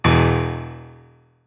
Outlaw Piano Stab .wav